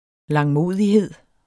Udtale [ lɑŋˈmoˀðiˌheðˀ ]